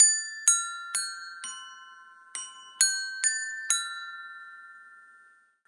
大本钟5小时5个钟我的祖母钟
它是我奶奶客厅里的钟的录音，我奶奶最近去世了。
标签： 大奔 时钟 奶奶 姥姥 家居 客厅 稳压器 在家里 滴答
声道立体声